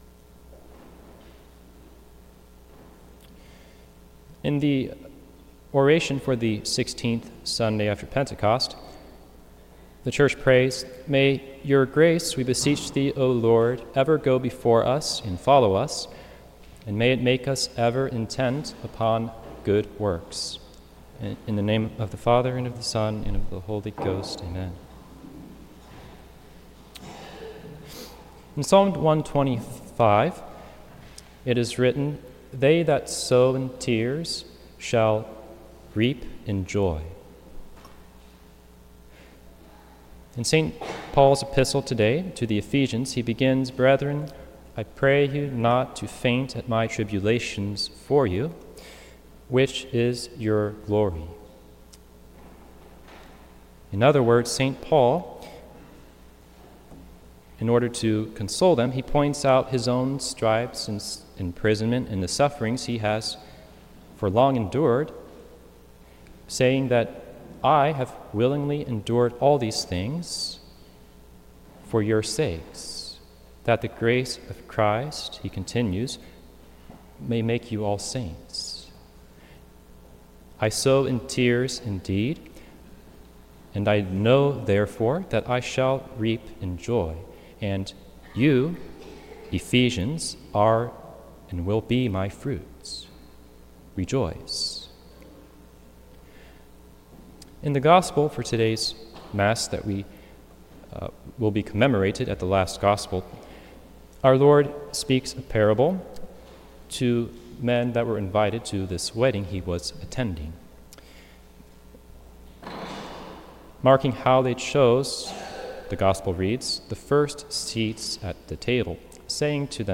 This entry was posted on Sunday, September 8th, 2024 at 9:34 am and is filed under Sermons.